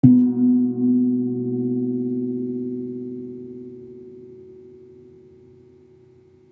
Gamelan / Gong
Gong-B2-f.wav